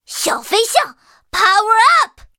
M4A3E2小飞象强化语音.OGG